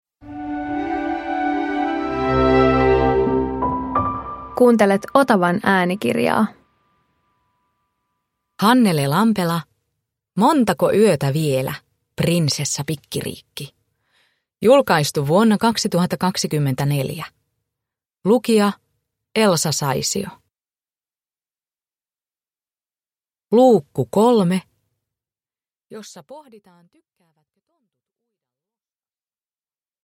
Montako yötä vielä, Prinsessa Pikkiriikki 3 – Ljudbok
Uppläsare: Elsa Saisio